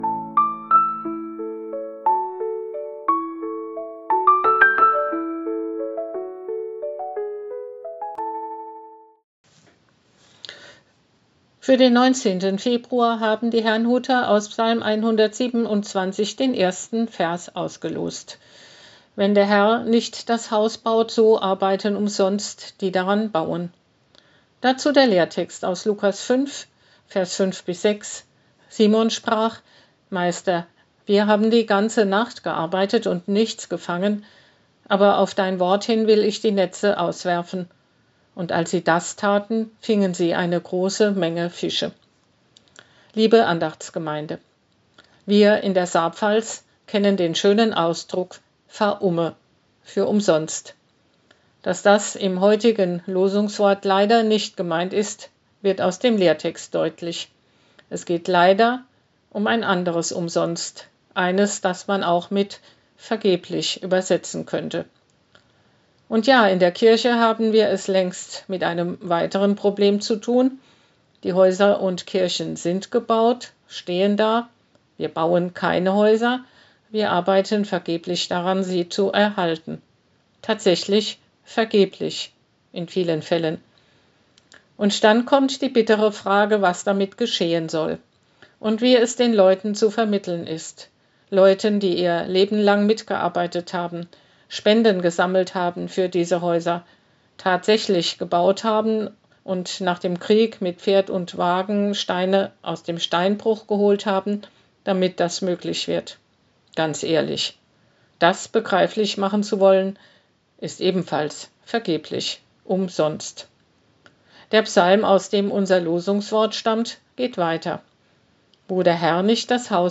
Losungsandacht für Mittwoch, 19.05.2025